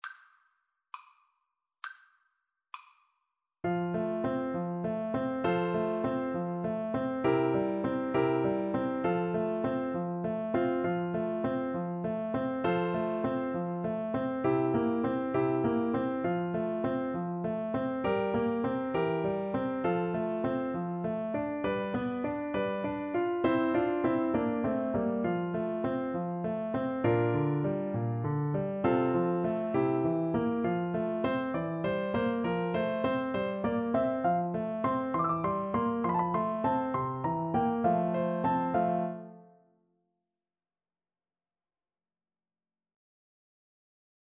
F major (Sounding Pitch) G major (Clarinet in Bb) (View more F major Music for Clarinet )
~ = 100 Fršhlich
6/8 (View more 6/8 Music)
Classical (View more Classical Clarinet Music)